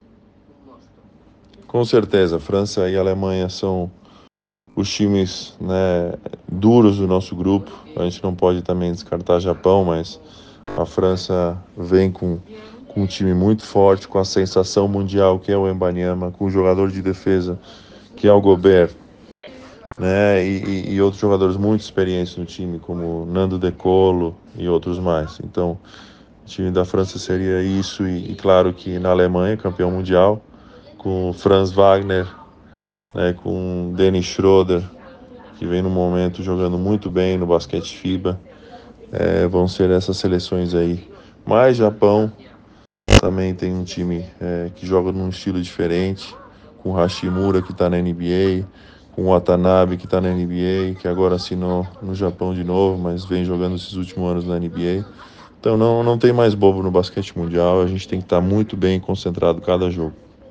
Entrevista com Tiago Splitter – Auxiliar técnico da Seleção Brasileira de Basquete